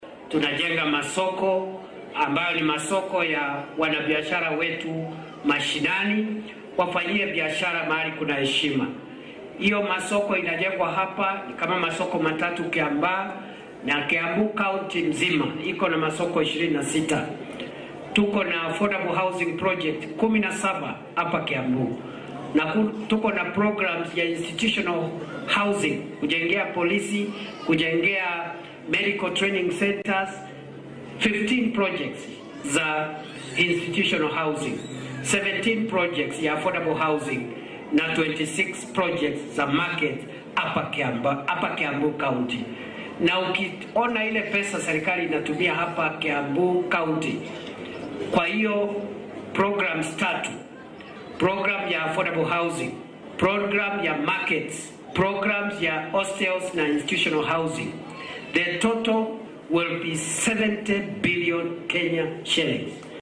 Arrintan ayuu maanta ka sheegay deegaanka Kiambaa ee dowlad deegaanka Kiambu oo uu uga qayb galay barnaamij awoodsiin dhaqaale ah oo lagu qabtay fagaaraha dugsiga hoose ee Gachie .